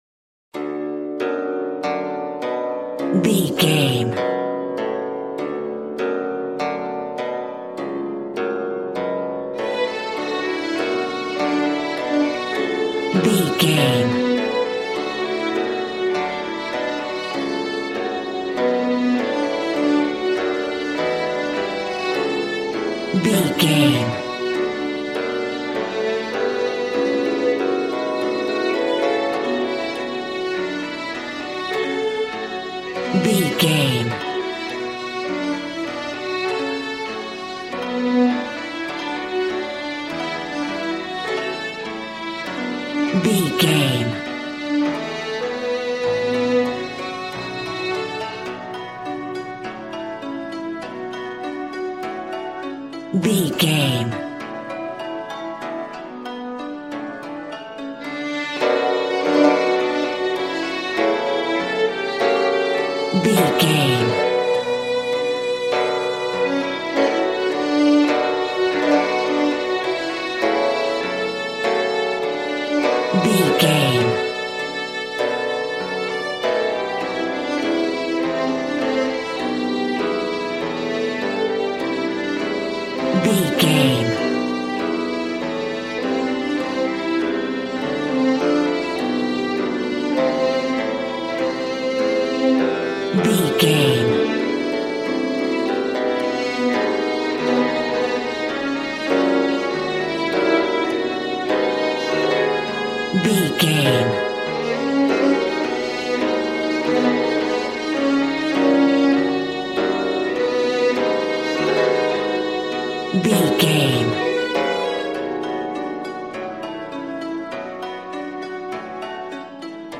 Aeolian/Minor
smooth
conga
drums